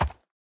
minecraft / sounds / mob / horse / soft1.ogg